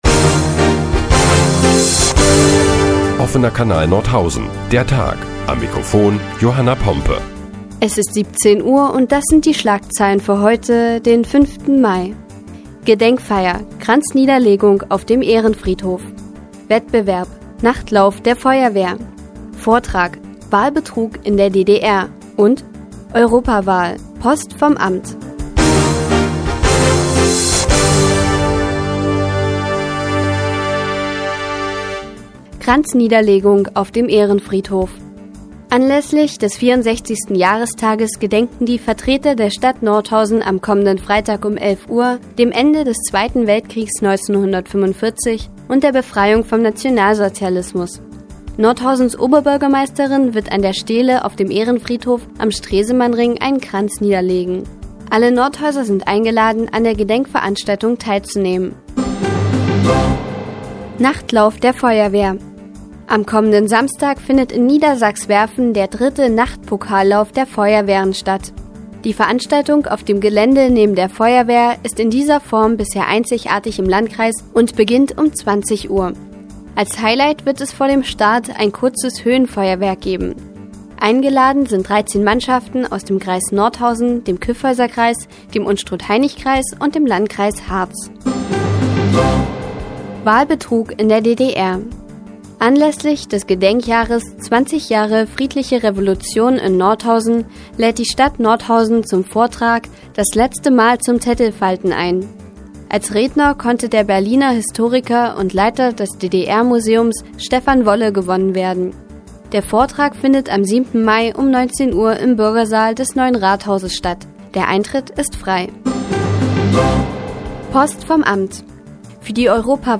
Die tägliche Nachrichtensendung des OKN ist nun auch in der nnz zu hören. Heute geht es unter anderem um eine Kranzniederlegung auf dem Ehrenfriedhof und den Nachtlauf der Feuerwehr.